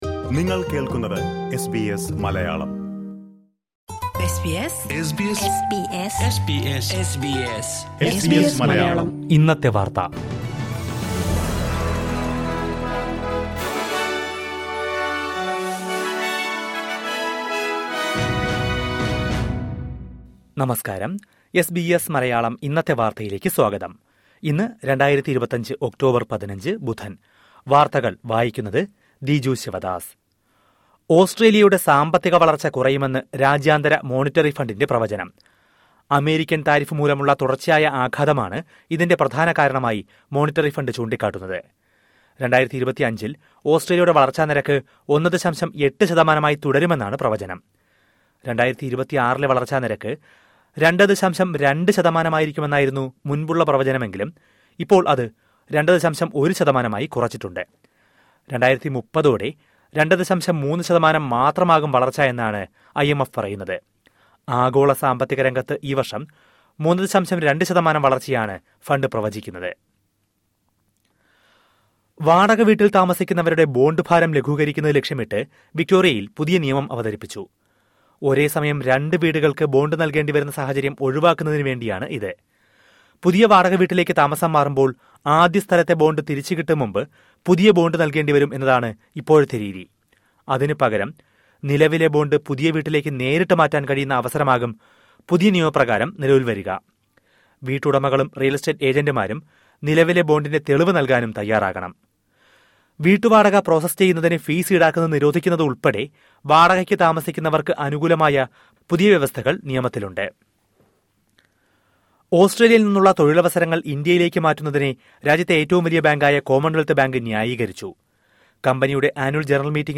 2025 ഒക്ടോബർ 15ലെ ഓസ്ട്രേലിയയിലെ ഏറ്റവും പ്രധാന വാർത്തകൾ കേൾക്കാം...